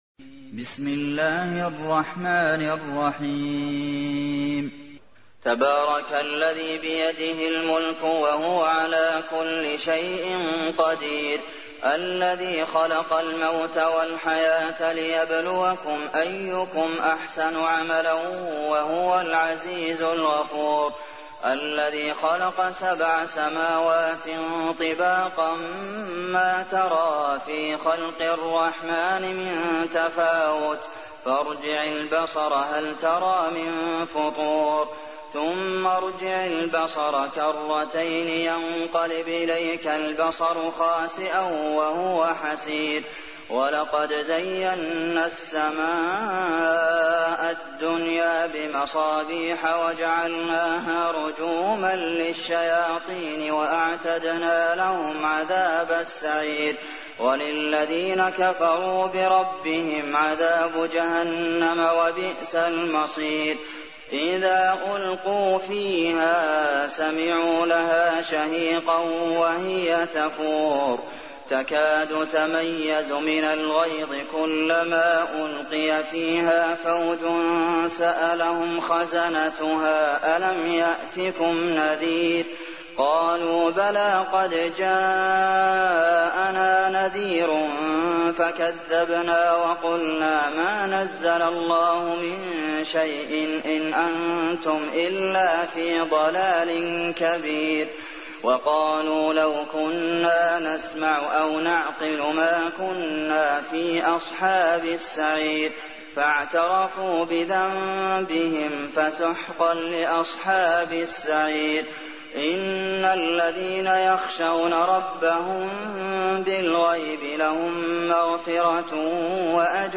تحميل : 67. سورة الملك / القارئ عبد المحسن قاسم / القرآن الكريم / موقع يا حسين